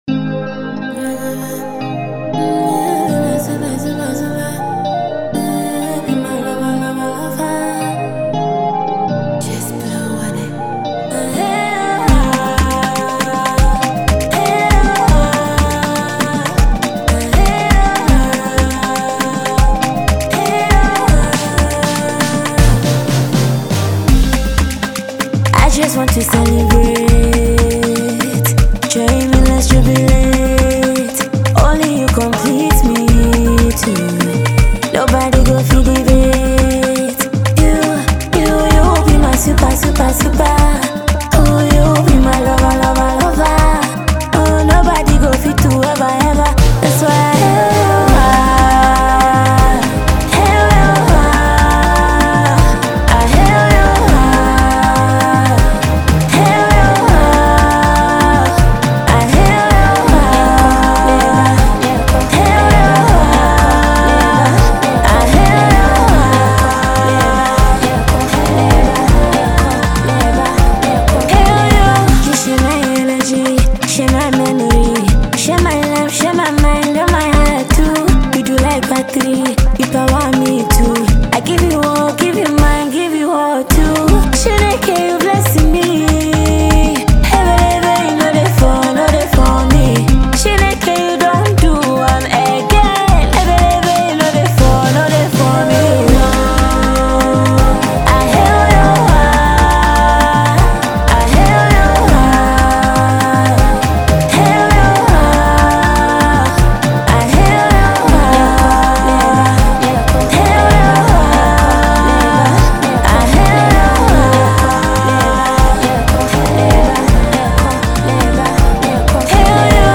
Enjoy this amazing studio track.